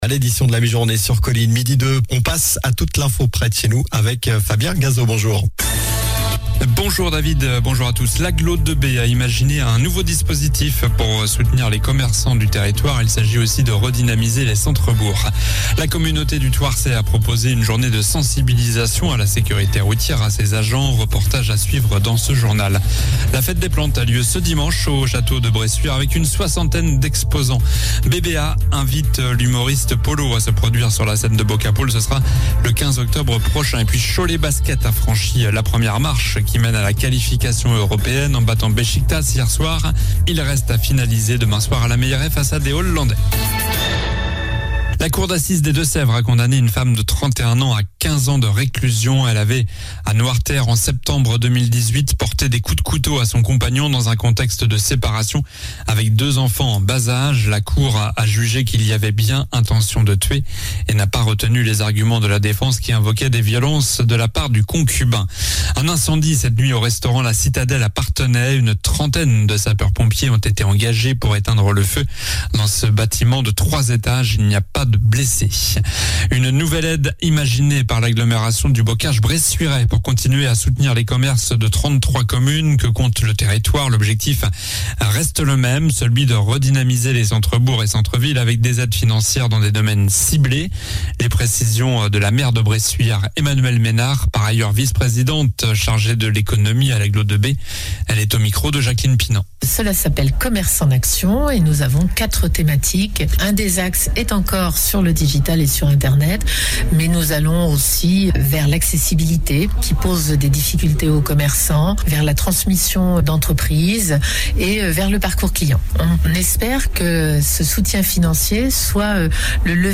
Journal du jeudi 29 septembre (midi)